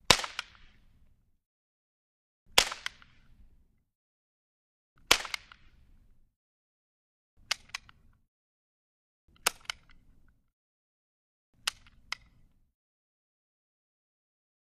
Cap Gun | Sneak On The Lot
Toy Cap Gun: Bangs And Misfires. Mono